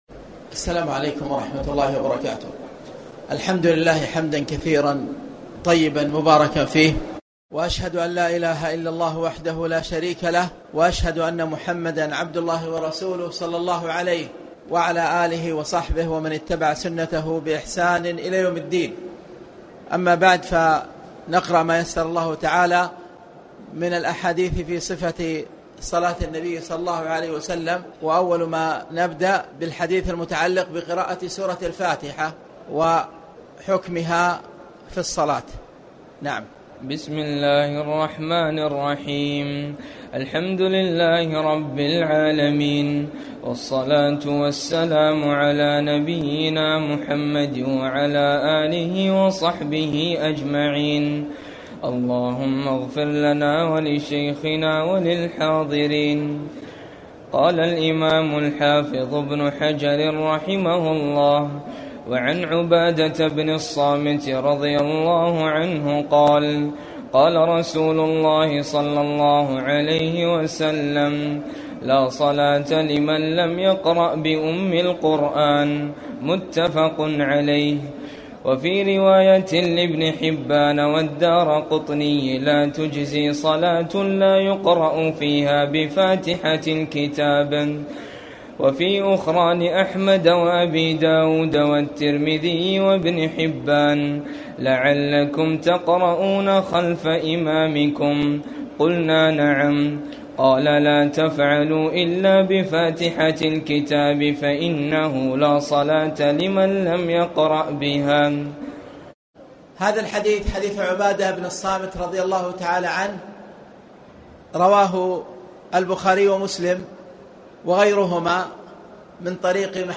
تاريخ النشر ١٠ رمضان ١٤٣٨ هـ المكان: المسجد الحرام الشيخ